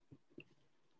Preached on the 28th of Oct 2020 during the Bible Study on 1 Samuel